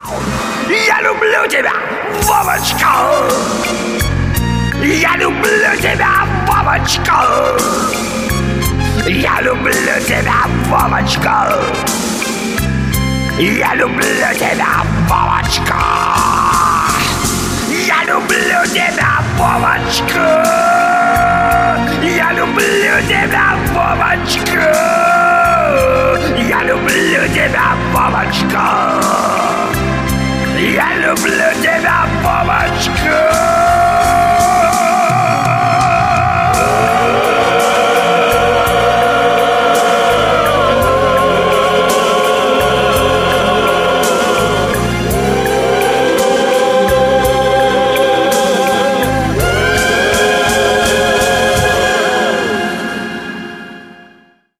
• Качество: 192, Stereo
веселые
русский шансон
Блатняк